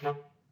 DCClar_stac_D2_v1_rr1_sum.wav